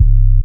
808 (Lemon).wav